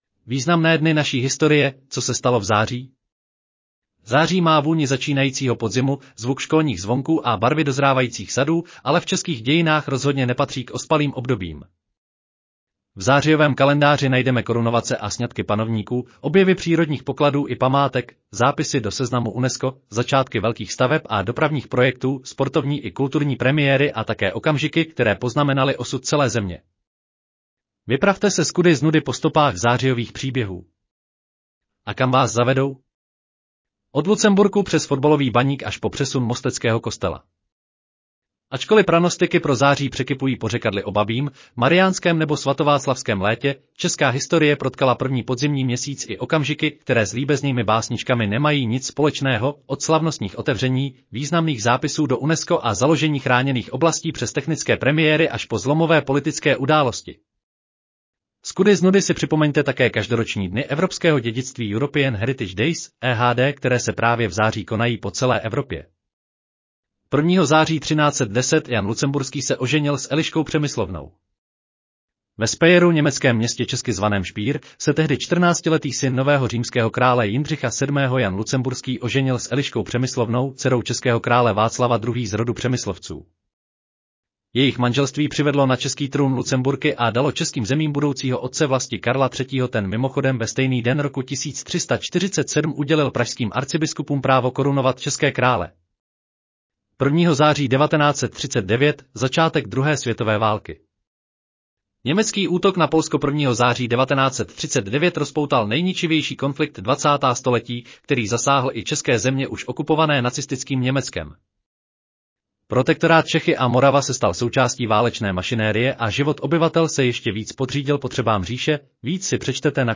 Audio verze článku Významné dny naší historie: co se stalo v září?